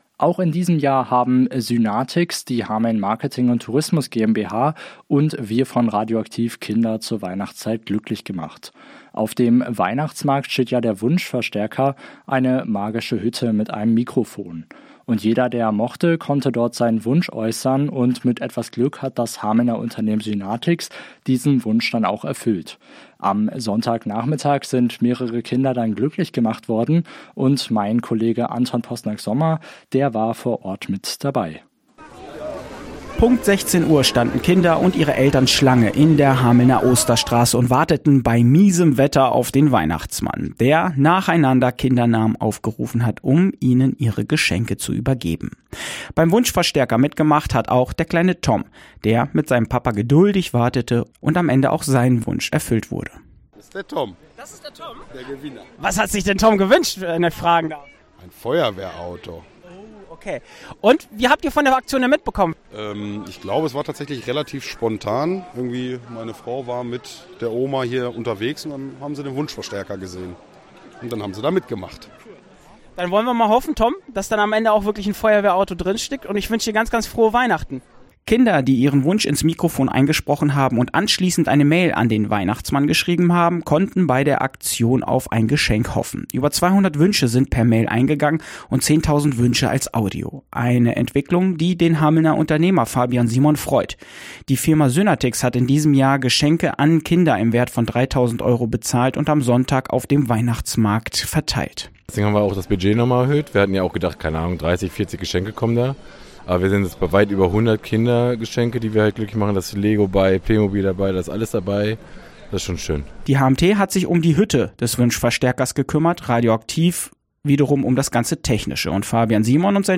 Aktion Wunschverstärker: Glückliche Kinder bei der Bescherung auf dem Hamelner Weihnachtsmarkt